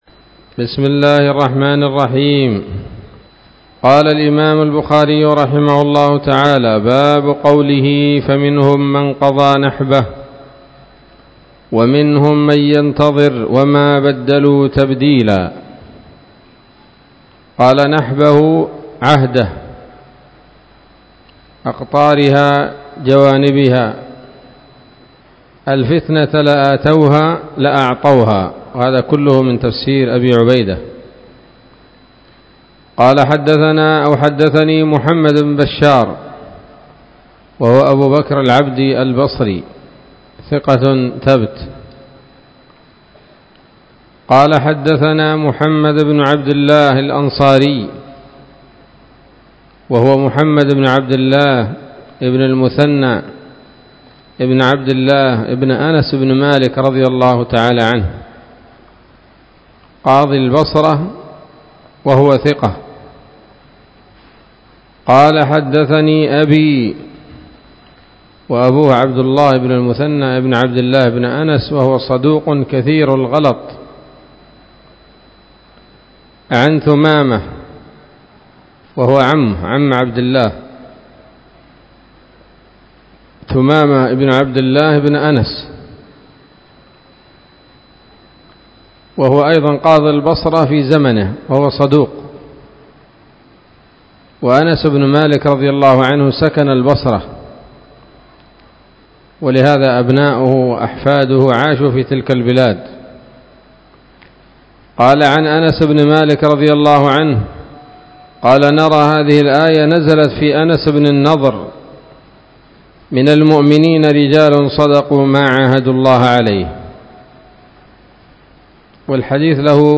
الدرس الثاني بعد المائتين من كتاب التفسير من صحيح الإمام البخاري